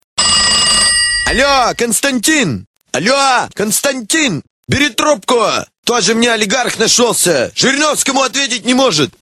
Прикольные рингтоны